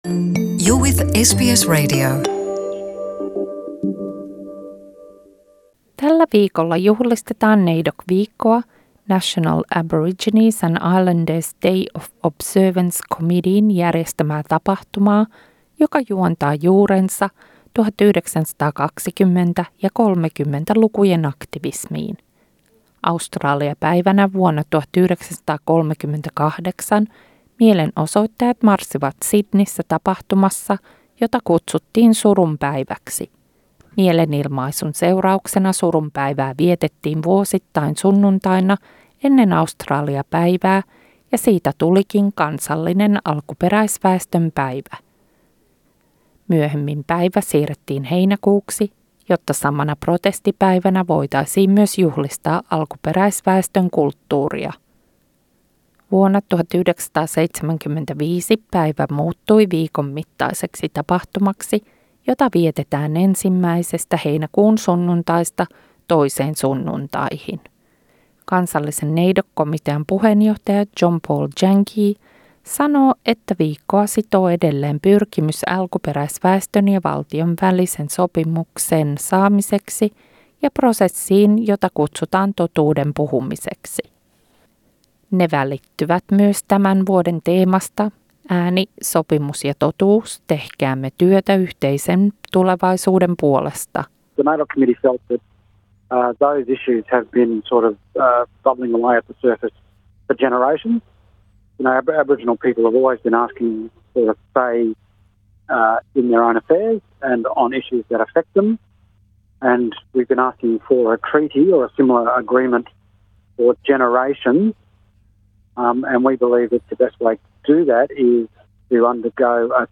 Tässä raportissa kuulemme kuinka NAIDOC viikko sai alkuunsa ja mitä se oikestaan merkitsee?